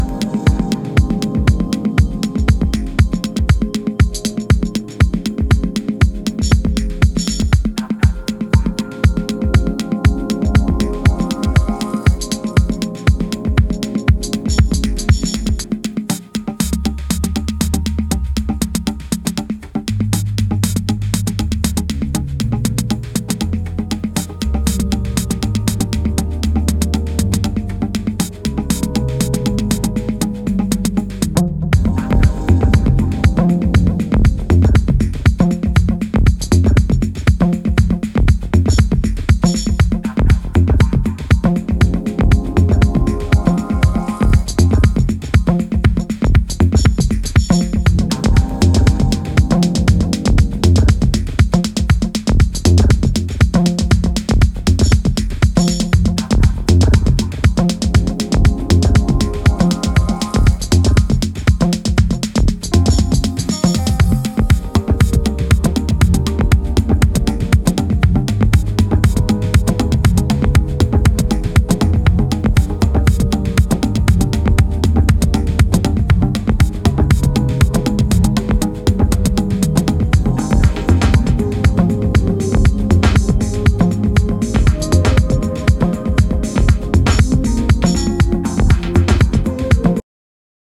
内省的かつドリーミーなタッチで再構成したそちらも、間違いない仕上がりです！